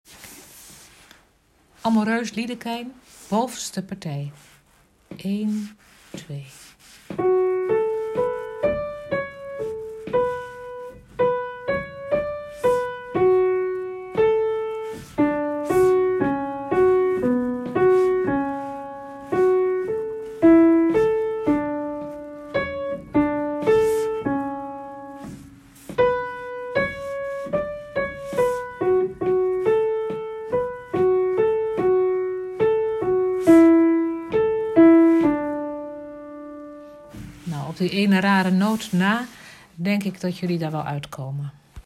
bovenpartij